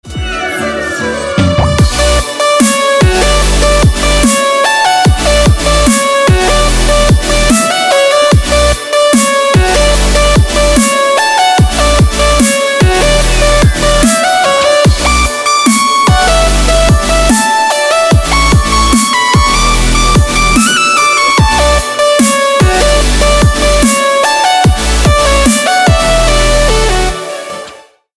• Качество: 256, Stereo
dance
Electronic
EDM
club
future bass